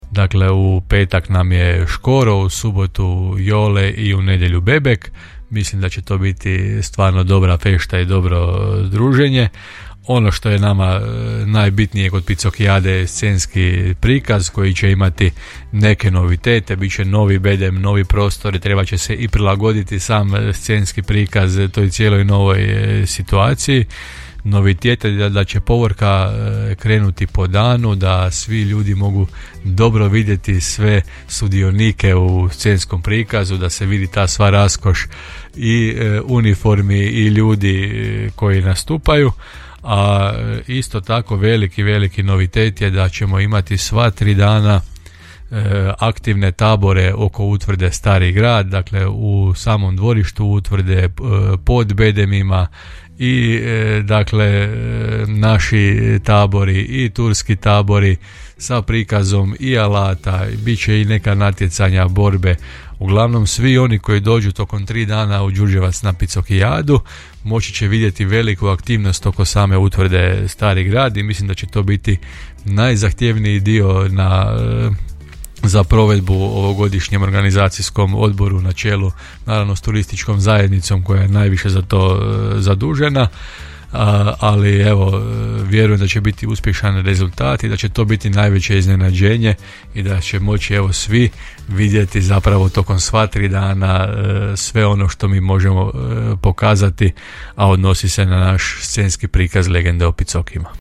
Poslušajte što nam je o pripremama za ovogodišnji program Picokijade rekao gradonačelnik Hrvoje Janči;